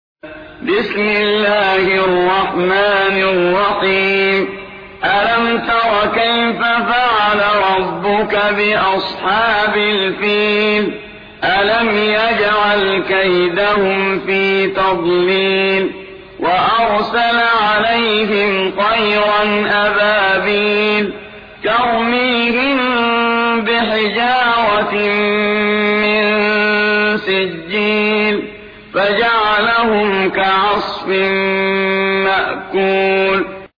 سورة الفيل / القارئ